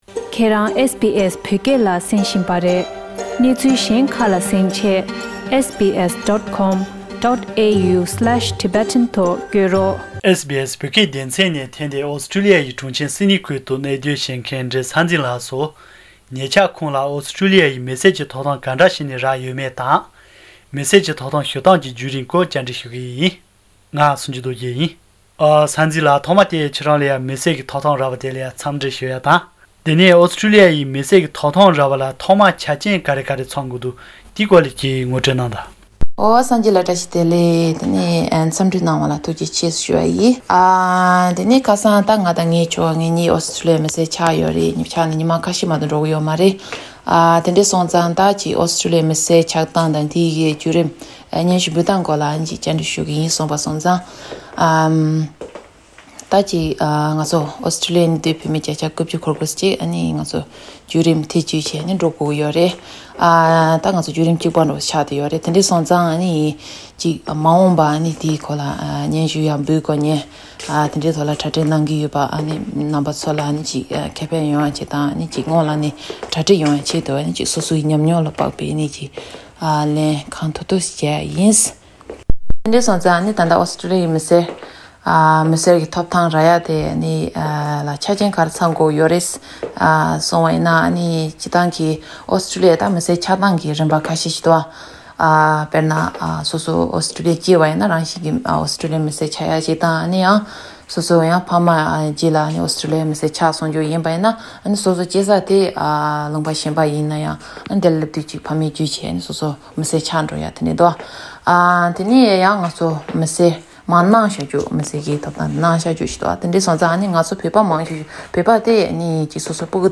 མི་སེར་གྱི་ཐོབ་ཐང་ཞུ་སྟངས་ཀྱི་བརྒྱུད་རིམ་སྐོར་བཅར་འདྲི།